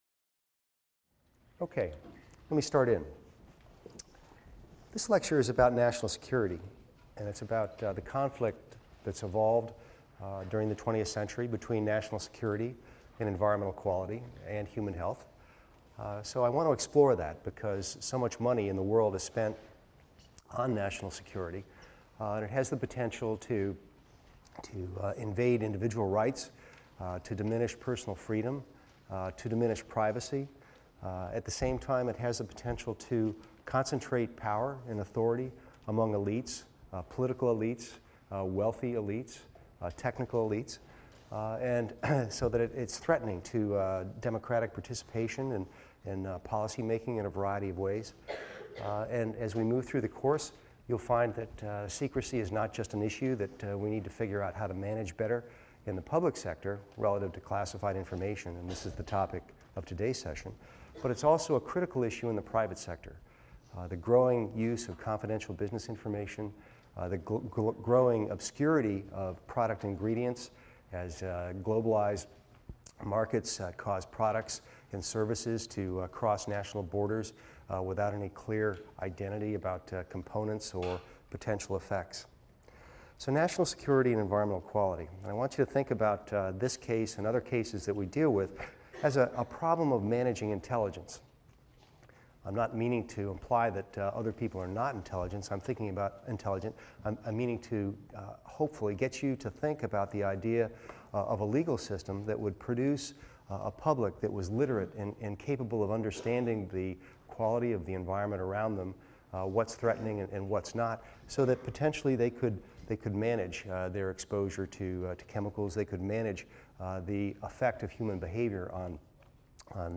EVST 255 - Lecture 3 - Nuclear Experiments | Open Yale Courses